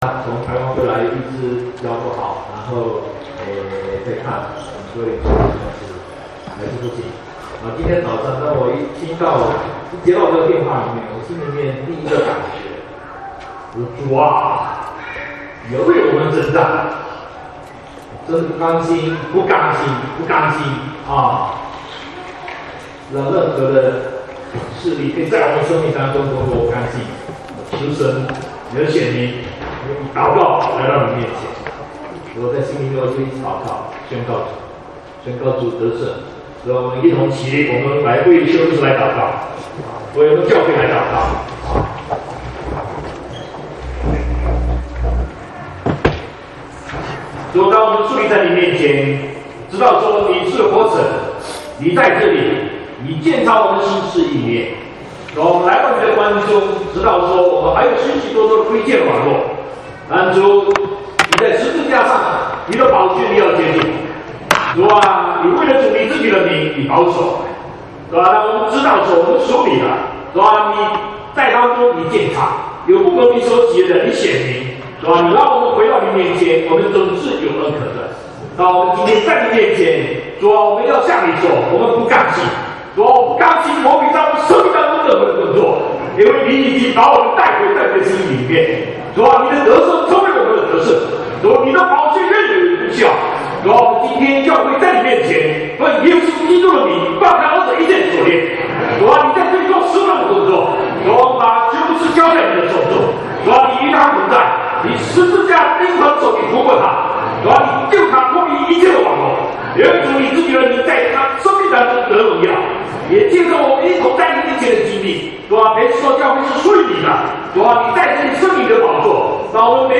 國語堂講道